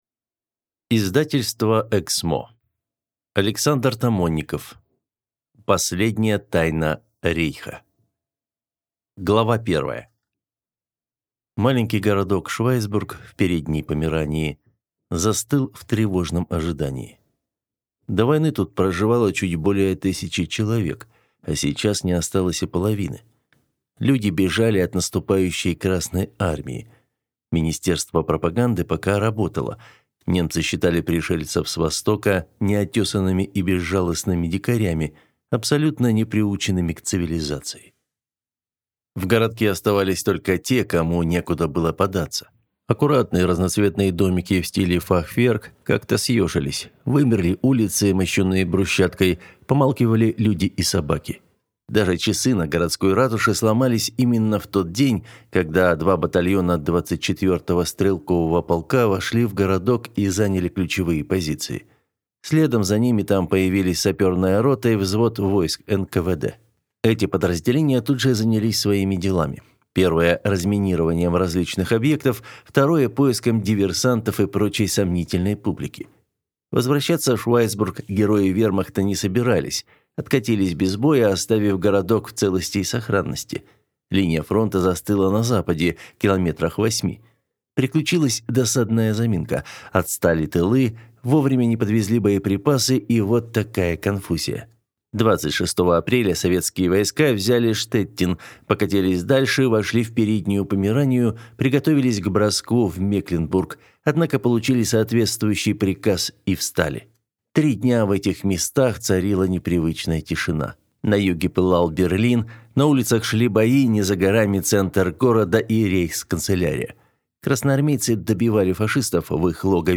Аудиокнига Последняя тайна рейха | Библиотека аудиокниг